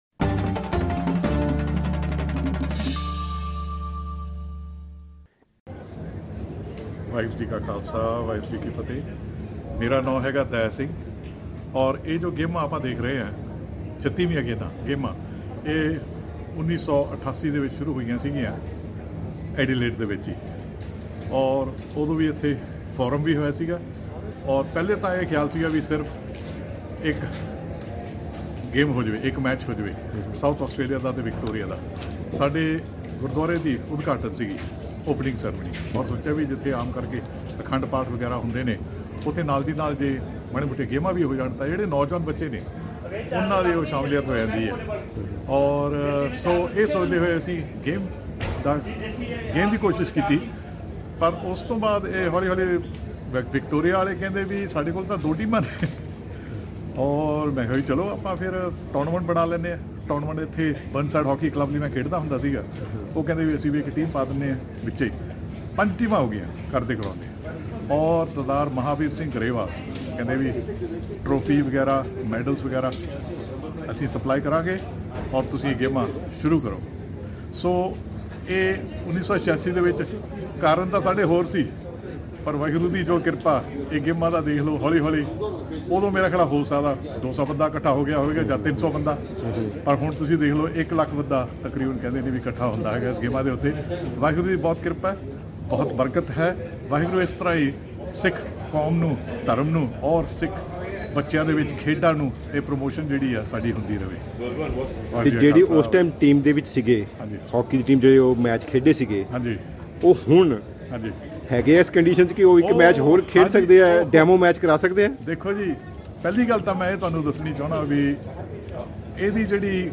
36ਵੀਆਂ ਆਸਟ੍ਰੇਲੀਅਨ ਸਿੱਖ ਖੇਡਾਂ ਦੌਰਾਨ ਭਾਈਚਾਰੇ ਨਾਲ ਕੀਤੀਆਂ ਮੁਲਾਕਾਤਾਂ ਦੇ ਕੁੱਝ ਅੰਸ਼
ਧੰਨਵਾਦ ਹੈ ਭਾਈਚਾਰੇ ਦੇ ਉਨ੍ਹਾਂ ਸੁਹਿਰਦ ਸੱਜਣਾਂ ਦਾ ਜਿਹਨਾਂ ਨੇ ਖੇਡ ਮੇਲੇ ਦੌਰਾਨ ਸਮਾਂ ਕੱਢਦੇ ਹੋਏ ਐਸ ਬੀ ਐਸ ਦੀ ਟੀਮ ਨਾਲ ਗੱਲਾਬਾਤਾਂ ਕੀਤੀਆਂ ਅਤੇ ਪ੍ਰੋਗਰਾਮ ਨੂੰ ਹੋਰ ਨਿਖਾਰਨ ਲਈ ਵੱਡਮੁੱਲੇ ਵੀਚਾਰ ਵੀ ਪੇਸ਼ ਕੀਤੇ।